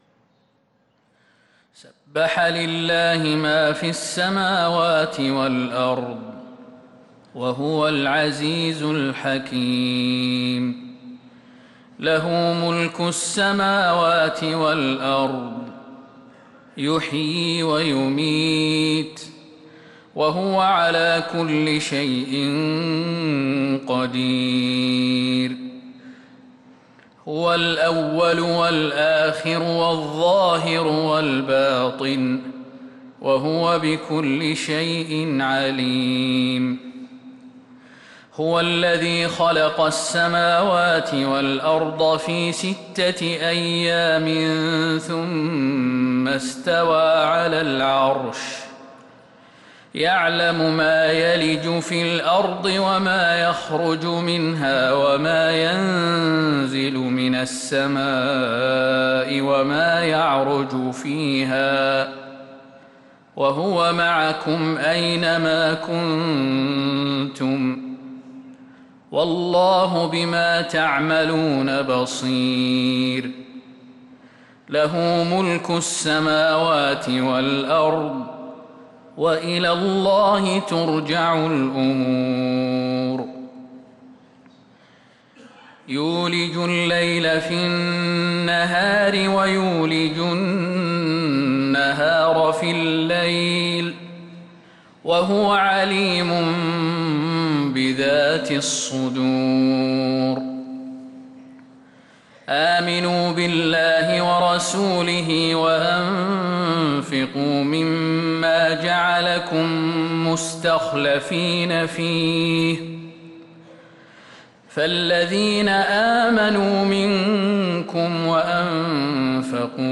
سورة الحديد كاملة للشيخ خالد المهنا مجمعة من فجريات شهرجمادى الآخرة 1447هـ > السور المكتملة للشيخ خالد المهنا من الحرم النبوي 🕌 > السور المكتملة 🕌 > المزيد - تلاوات الحرمين